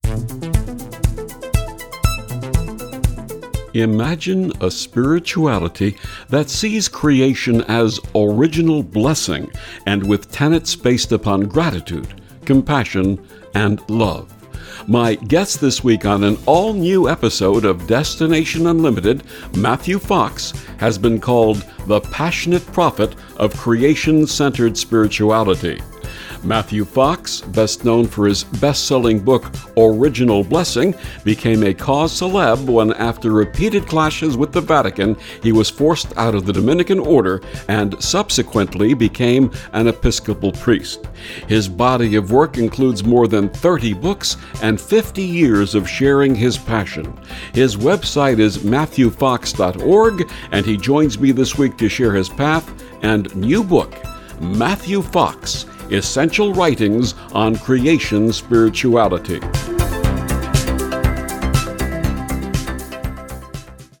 Destination-Unlimited-Audio-Promo-5-18-22-Matthew-Fox.mp3